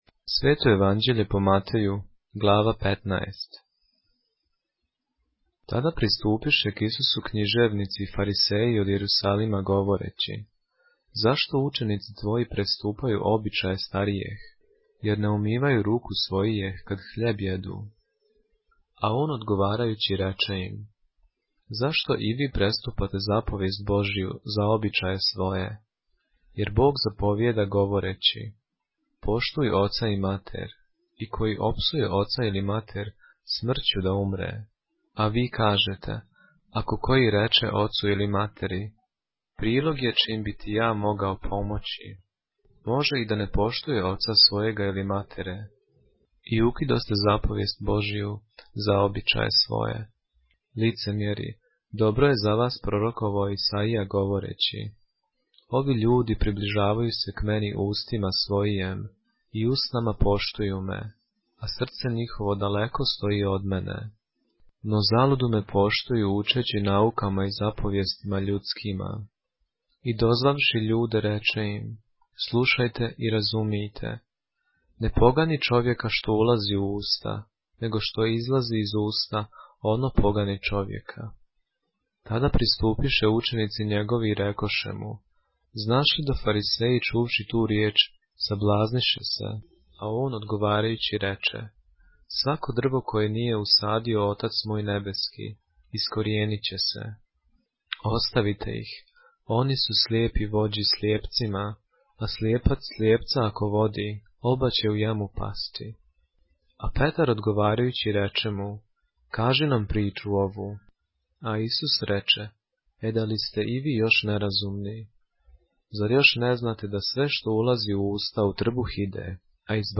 поглавље српске Библије - са аудио нарације - Matthew, chapter 15 of the Holy Bible in the Serbian language